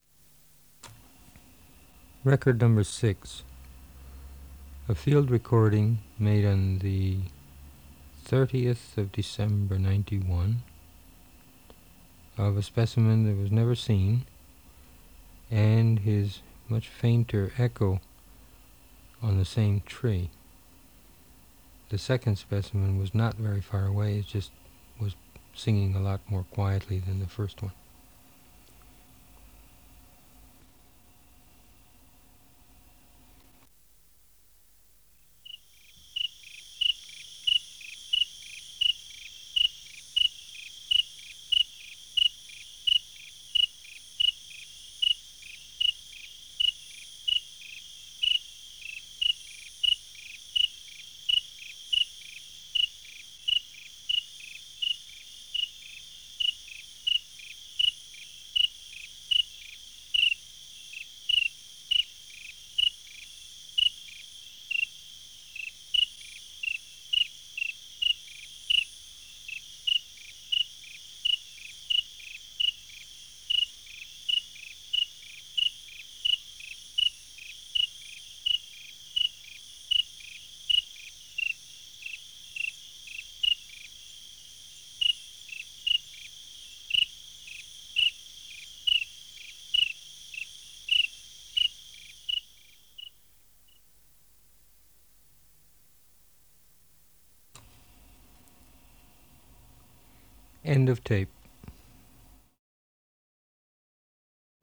Substrate/Cage: on tree
A second specimen on same tree, not far away but singing more quietly
Reflector: Parabolic dish
Recorder: Sony TC-D5M (No Dolby)